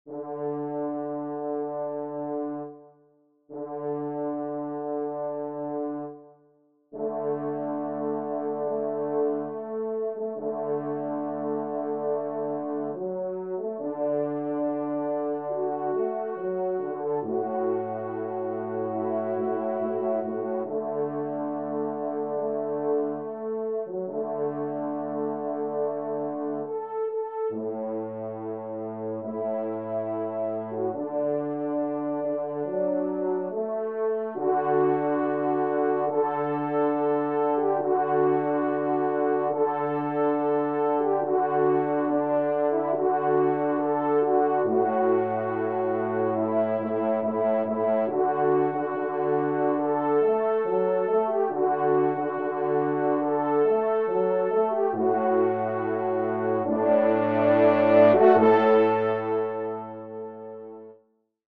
4ème Trompe